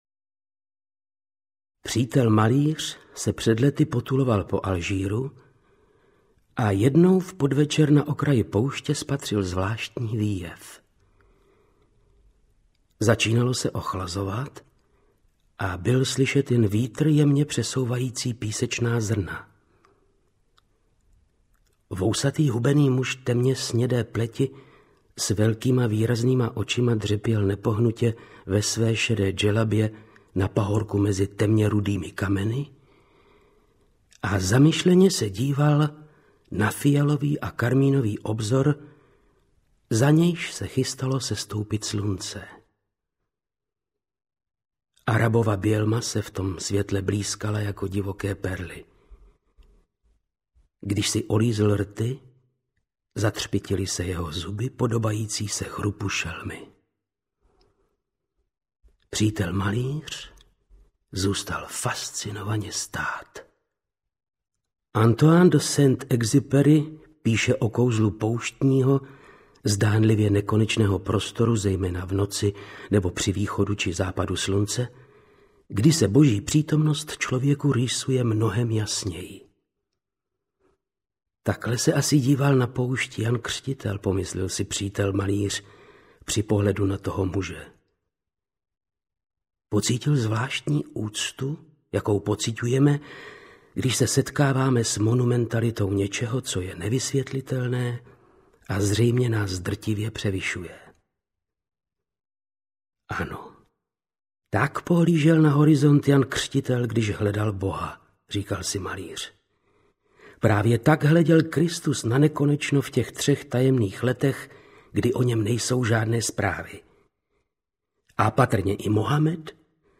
Černá krev audiokniha
Ukázka z knihy
• InterpretVáclav Knop